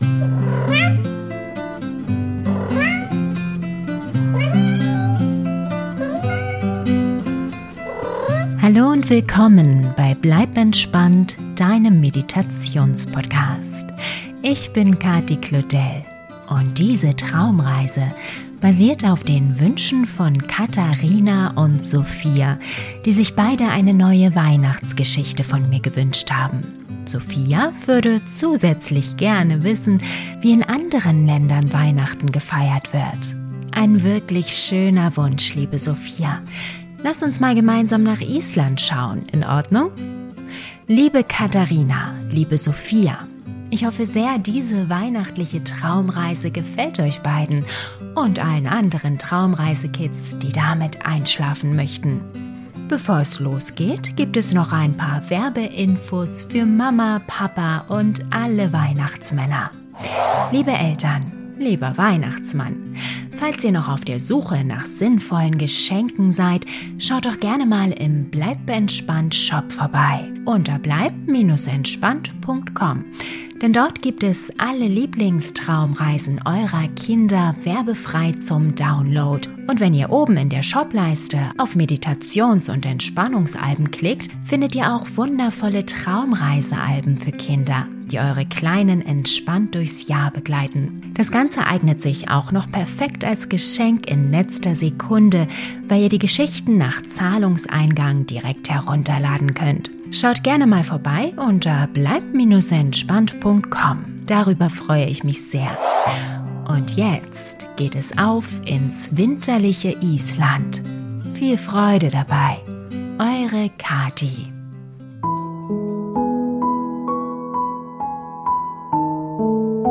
Beschreibung vor 1 Tag Diese Traumreise für Kinder  entführt dein Kind nach Island an Weihnachten!
Du kennst dein Kind am besten und weißt, wie es auf die Erzählung reagiert. Selbstverständlich wird die Gute Nacht Geschichte in einem sicheren und typisch isländisch-gemütlichem Rahmen erzählt und kann so für tiefe Ruhe und einen erholsamen Schlaf sorgen.